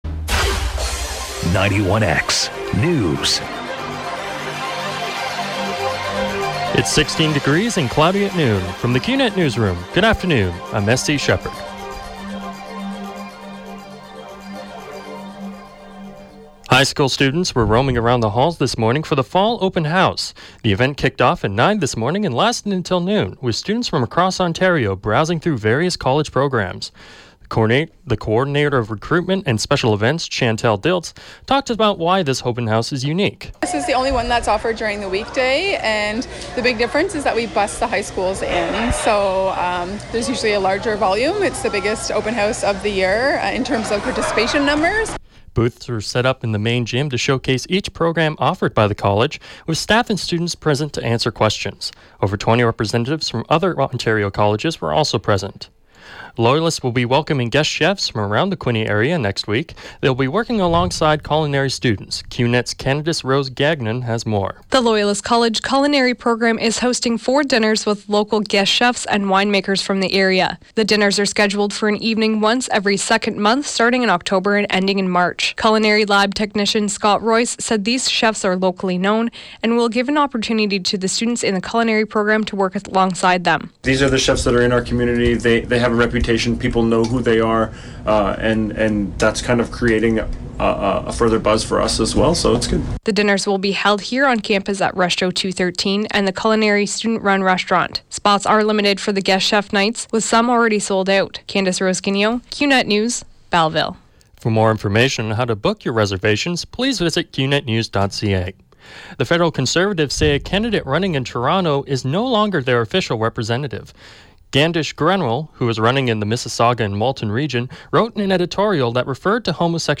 91X newscast – Wednesday, Oct. 7, 2015 – noon